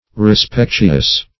Search Result for " respectuous" : The Collaborative International Dictionary of English v.0.48: Respectuous \Re*spec"tu*ous\ (r?*sp?k"t?*?s;135), a. 1.